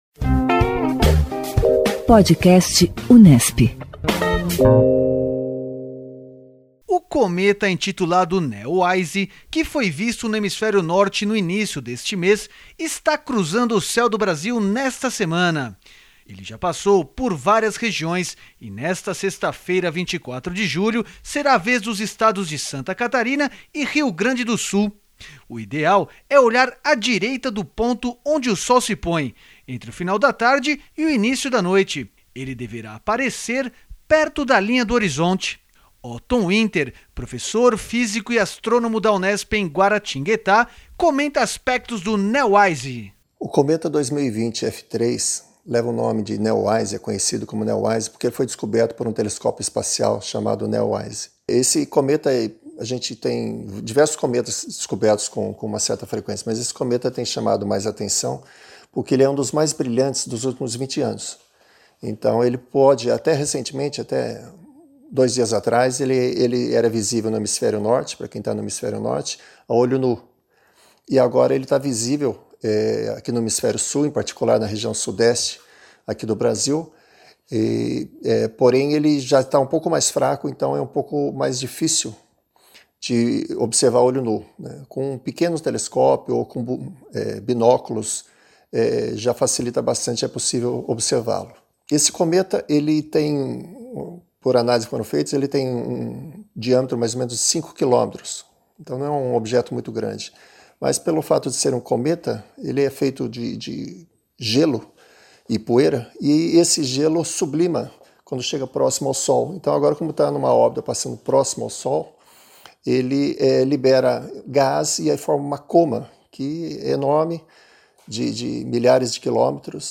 O Podcast Unesp / Assessoria de Comunicação e Imprensa da Reitoria da Unesp traz entrevistas com professores, pesquisadores e alunos sobre pautas cotidianas da mídia brasileira, internacional e informações geradas na Universidade.